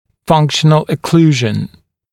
[‘fʌŋkʃ(ə)n(ə)l ə’kluːʒ(ə)n][‘фанкш(э)н(э)л э’клу:ж(э)н]функциональная окклюзия, функциональный прикус